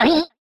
appear1.wav